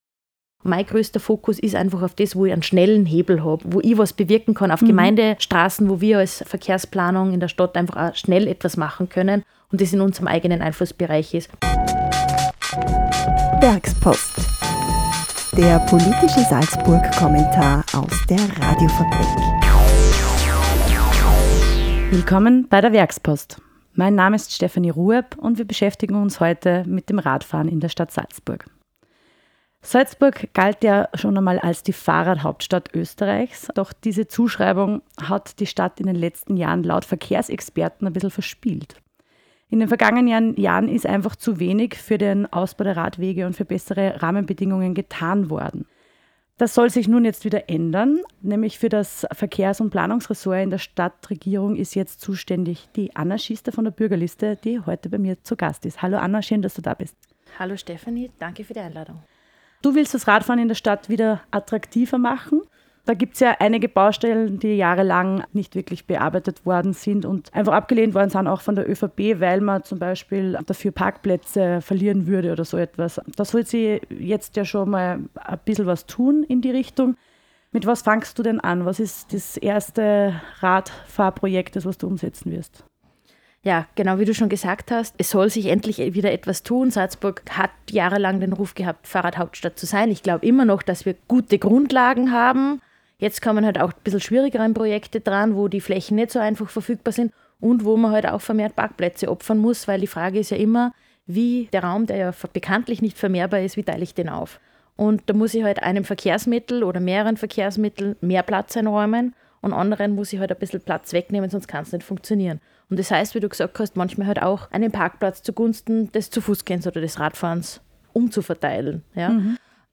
Der Werkspodcast vertieft das jeweilige Thema des Werkspost-Kommentars in einem Studiogespräch.